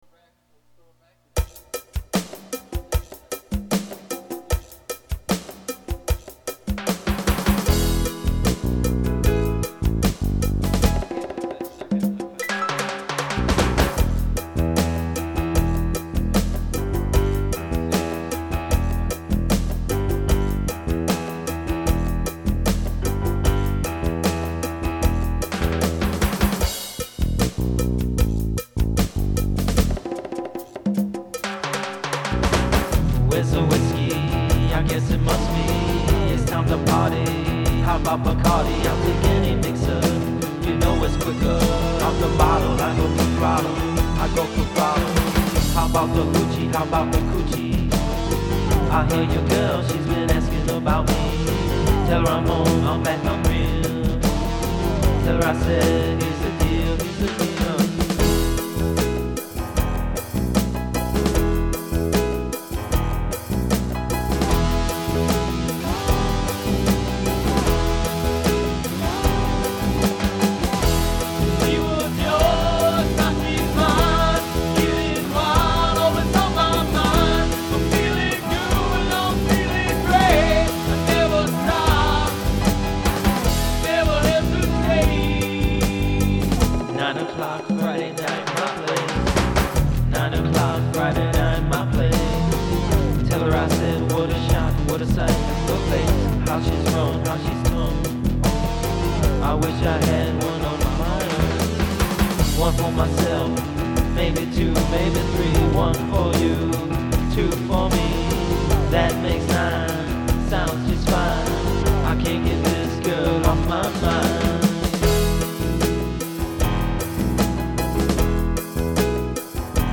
The 2 man rock band.